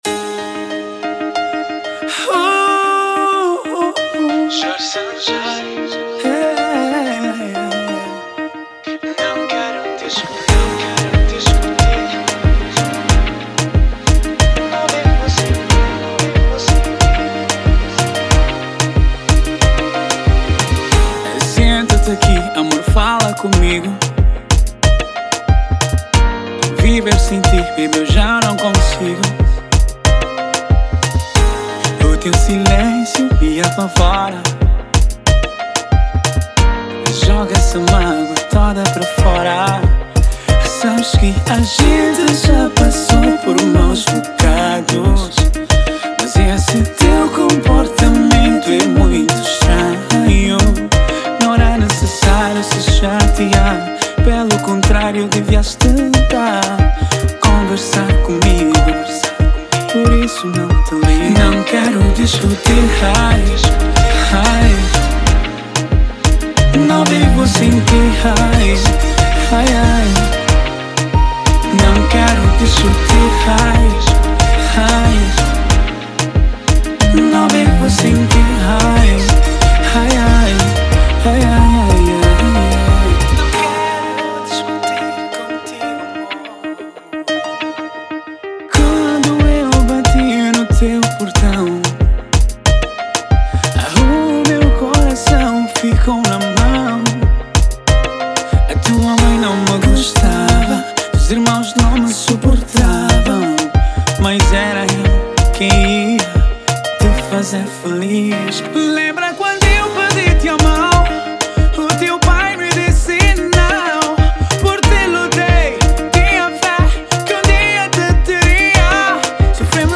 EstiloPop
Gênero : kizomba Letra